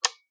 switch-b.ogg